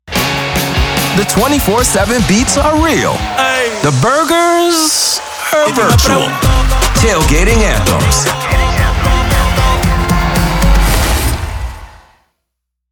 It goes beyond simply playing music, involving the expert layering of voiceovers, sound effects, music beds, and sonic logos that consistently reinforce your station’s brand and resonate with your target demographic. hip hop radio
Radio Imaging